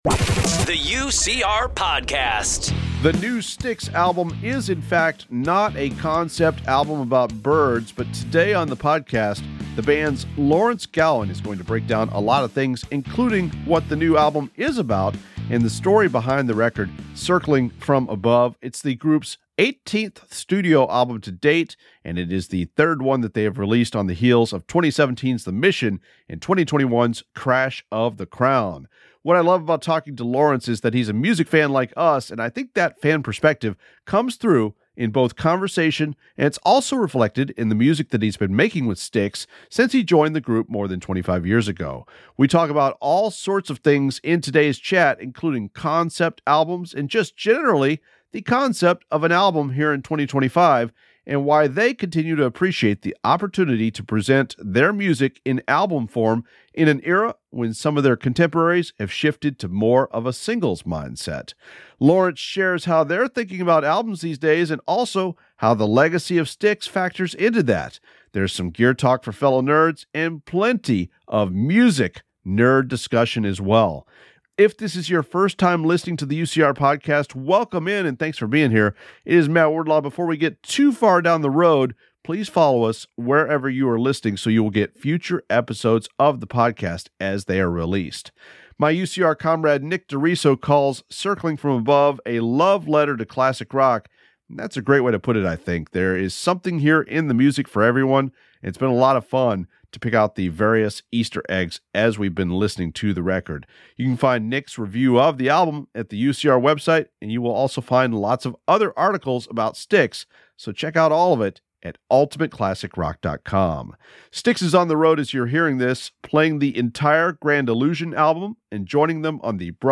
For more than 25 years, Lawrence Gowan has been part of Styx, but in conversation, one quickly discovers that he's also a big music fan.